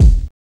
VNYL BOOM.wav